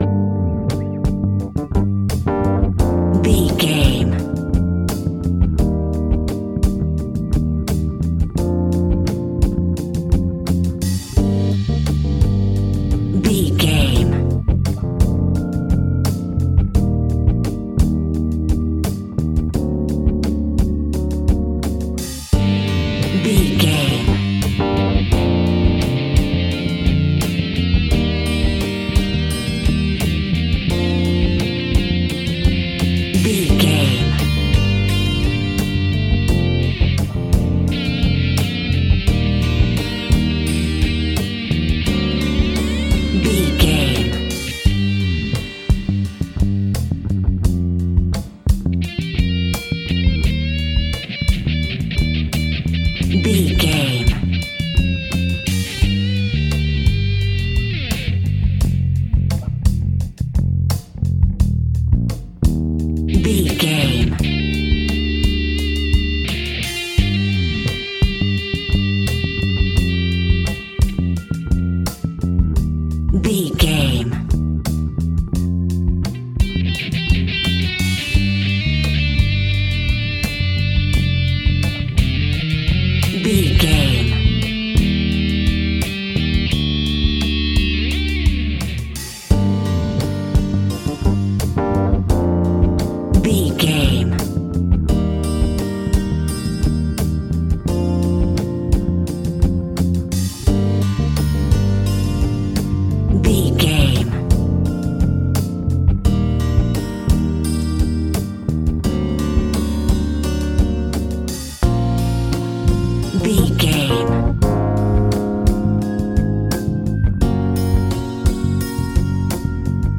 In-crescendo
Thriller
Aeolian/Minor
tension
ominous
haunting
eerie
Horror Pads
Horror Synths
Horror Ambience